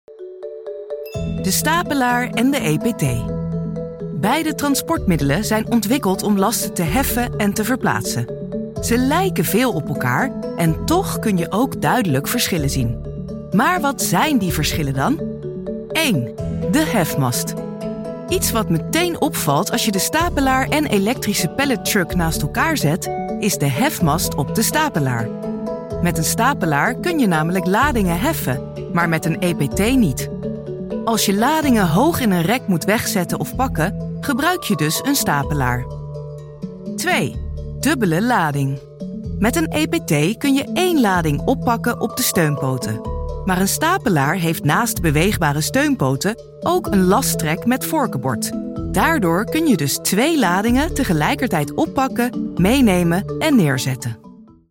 Natuurlijk, Speels, Toegankelijk, Veelzijdig, Vriendelijk
E-learning